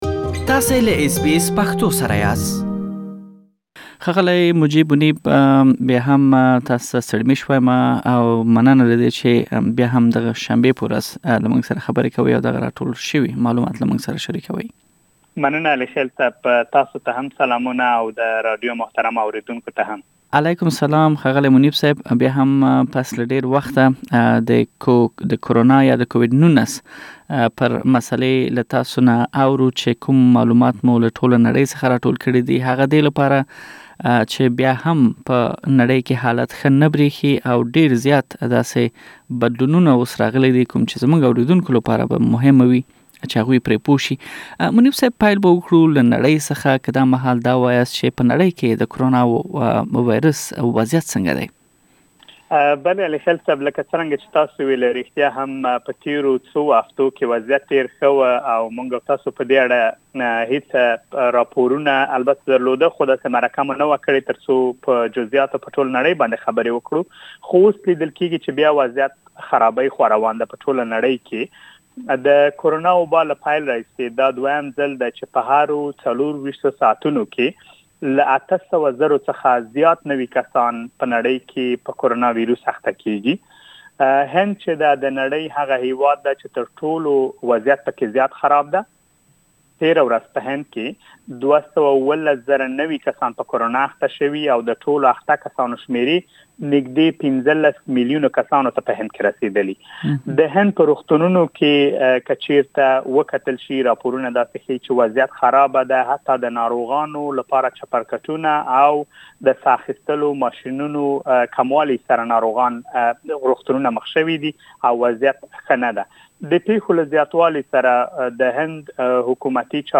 تاسو ته مو له ټولنې نړۍ څخه د کرونا ويروس مهم معلومات راټول کړي چې دا ټول پدې تيار شوي رپوټ کې اوريدلی شئ.